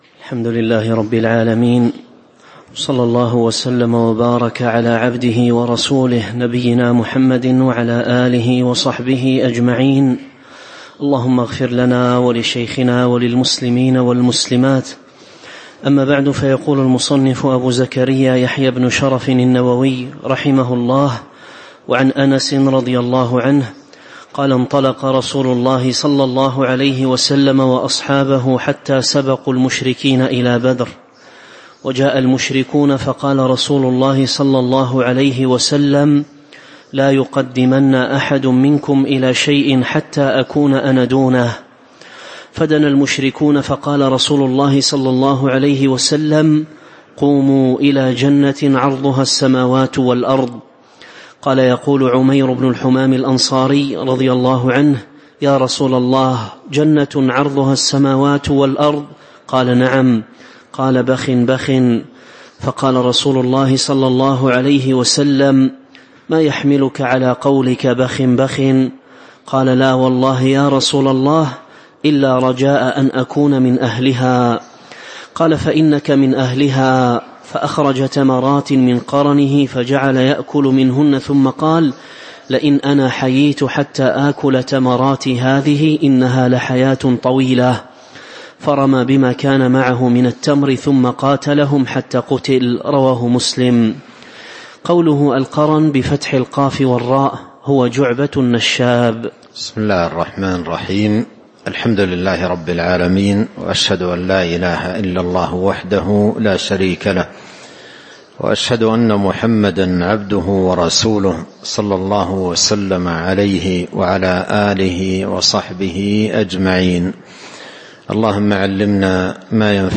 تاريخ النشر ٥ رجب ١٤٤٥ هـ المكان: المسجد النبوي الشيخ